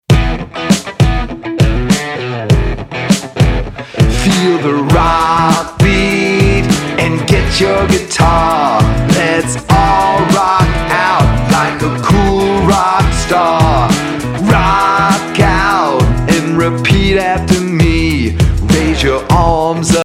fun, upbeat, dance, exercise and creative movement songs